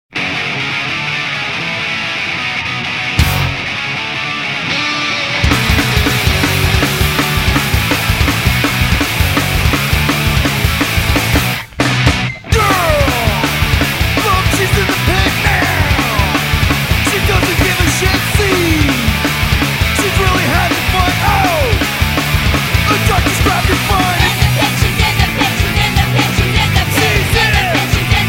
fast paced, pseudo melodic punk tunes